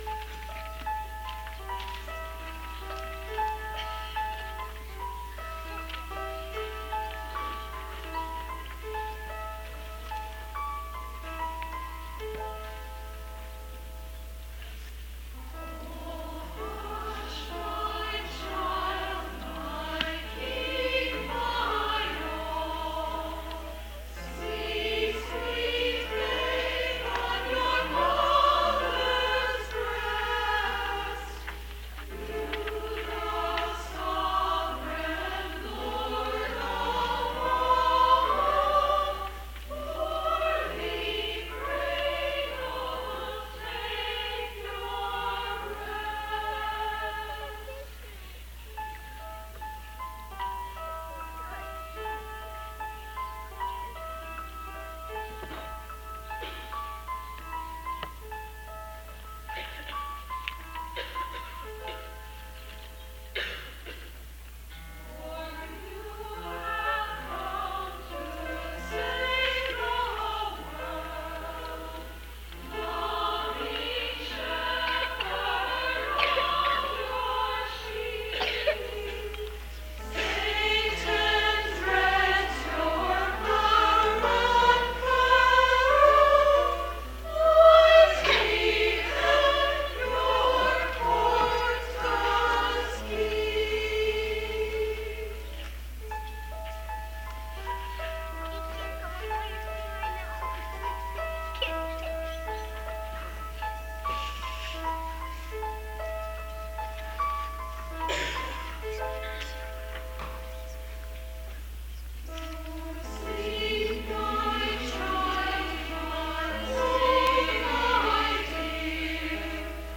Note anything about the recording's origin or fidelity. Christmas 1990, St. Paul's, Allentown St. Paul's Christmas Eve 1990